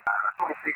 These EVPs Mention Our Names
during a session in his spirit room.